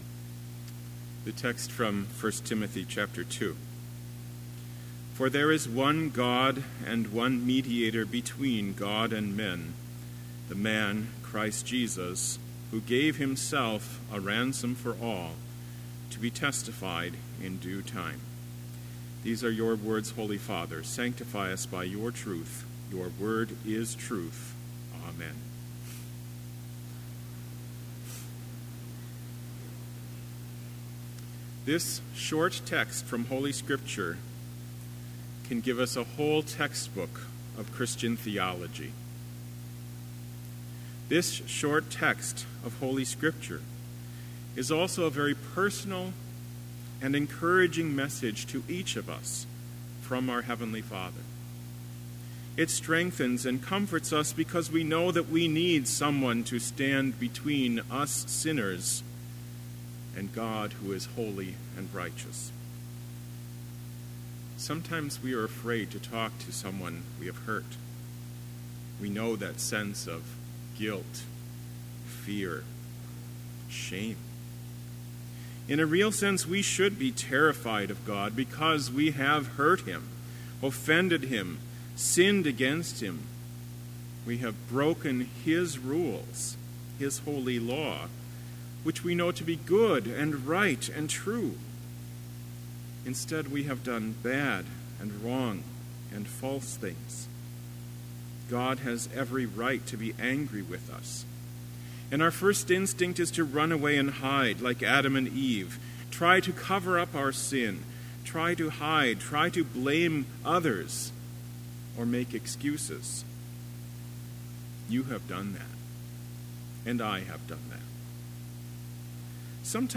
Complete Service
• Prelude
• Devotion
• Prayer
• Postlude